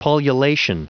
Prononciation du mot pullulation en anglais (fichier audio)
Prononciation du mot : pullulation